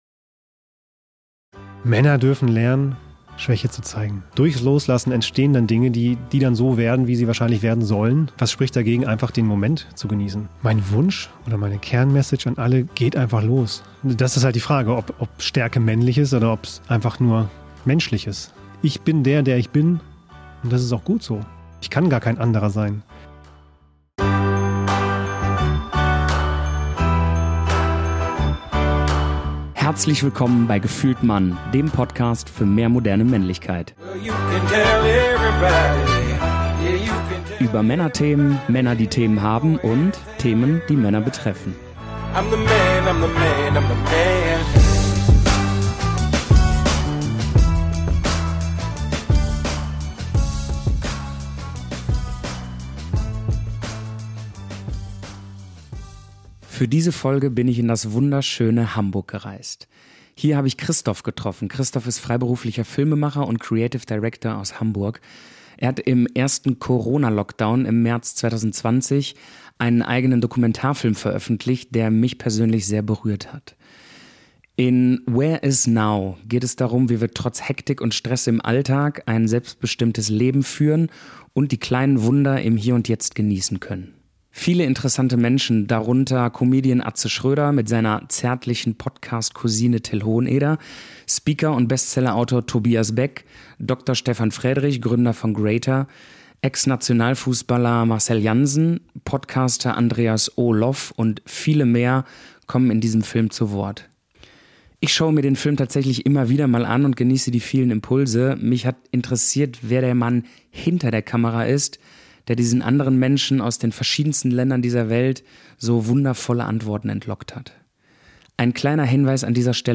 Für diese Folge bin ich in das wunderschöne Hamburg gereist.